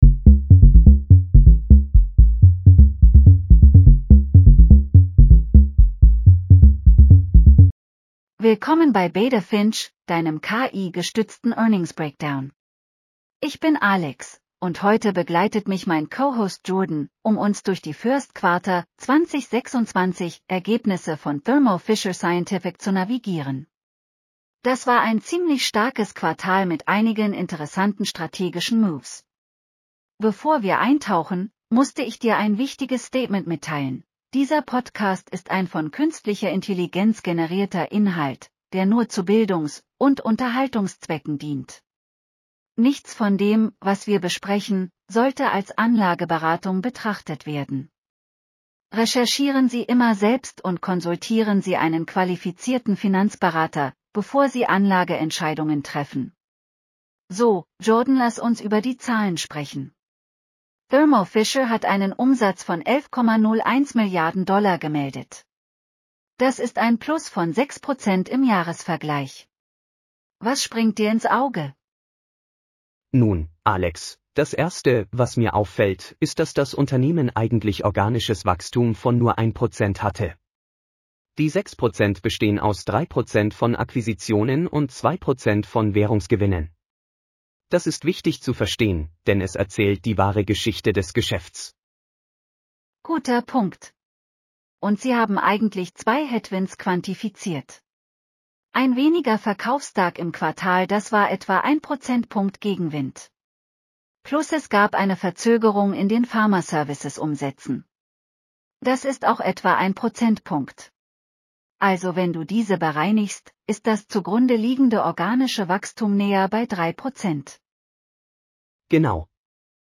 Thermo Fisher Scientific Q1 2026 earnings call breakdown.